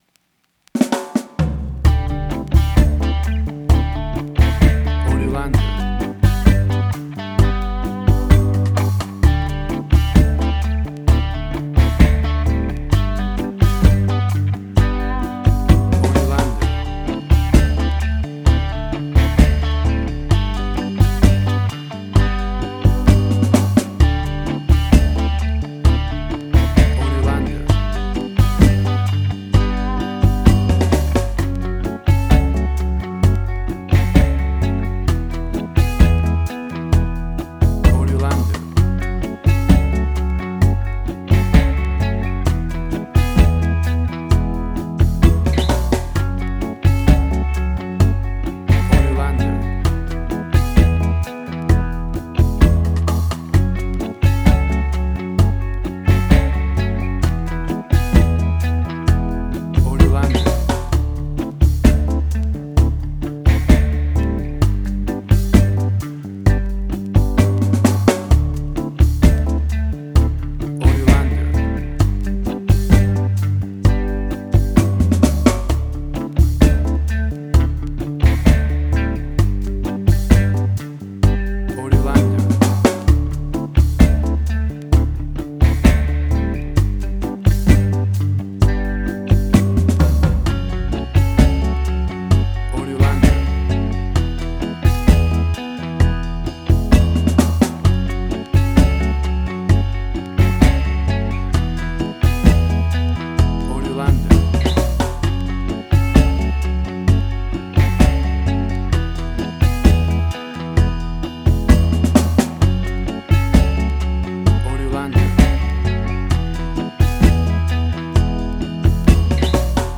Reggae caribbean Dub Roots
Tempo (BPM): 65